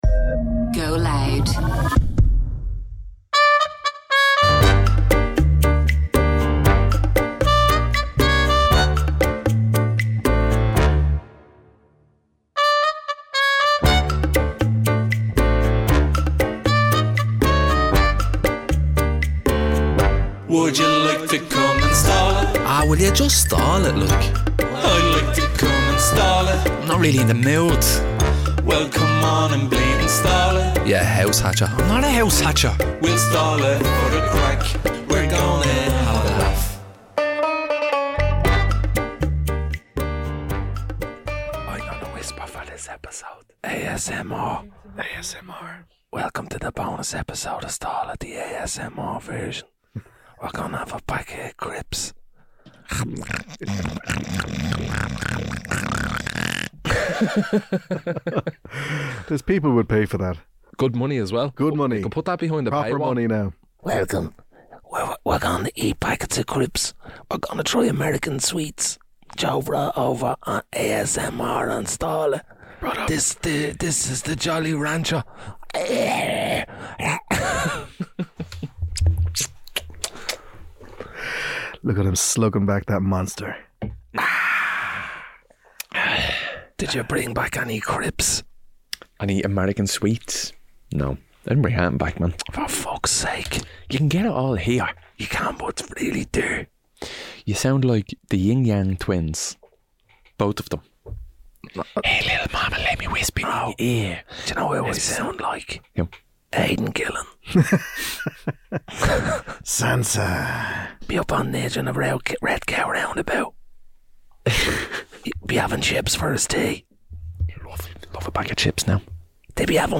He is greeted with unsettling ASMR routines
and the rare joy of a Stall It sing along.<
Comedy GoLoud Content provided by GoLoud.